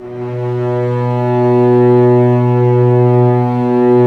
Index of /90_sSampleCDs/Roland L-CD702/VOL-1/STR_Vcs Bow FX/STR_Vcs Sordino